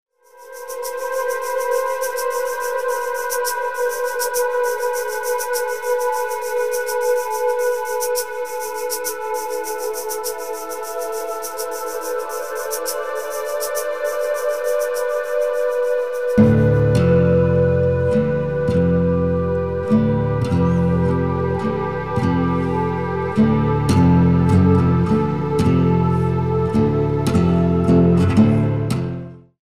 13 InstrumentalCompositions expressing various moods.